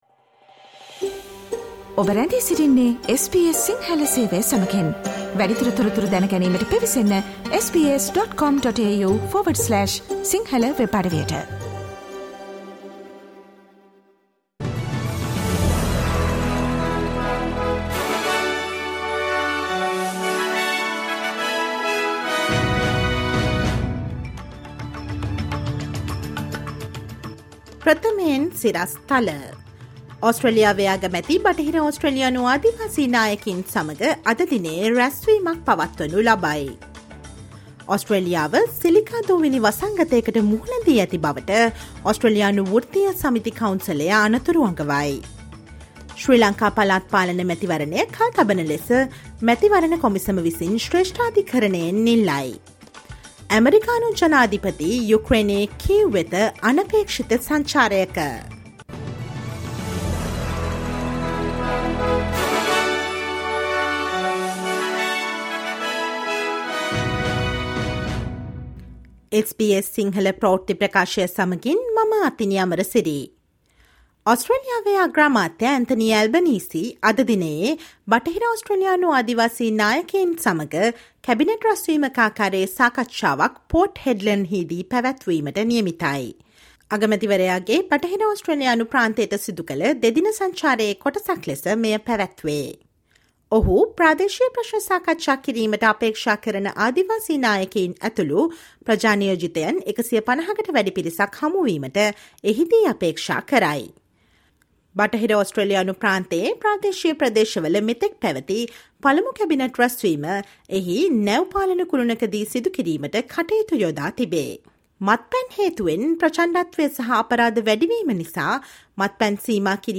ඔස්ට්‍රේලියාවේ නවතම පුවත් , ශ්‍රී ලංකාවෙන් වාර්තා වන පුවත් මෙන්ම විදෙස් පුවත් සහ ක්‍රීඩා පුවත් රැගත් SBS සිංහල සේවයේ 2023 පෙබරවාරි 211 වන දාවැඩසටහනේ ප්‍රවෘත්ති ප්‍රකාශයට සවන් දෙන්න.